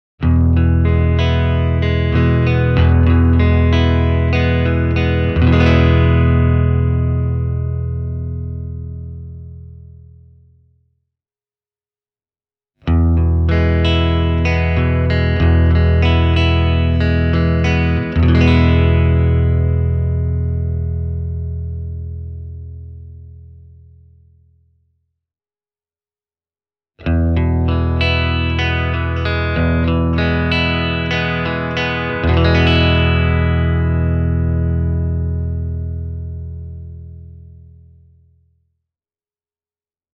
These three clips feature a Fender Telecaster…
bluetone-black-prince-reverb-e28093-fender-telecaster.mp3